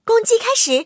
M22蝉开火语音1.OGG